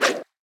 brush1.ogg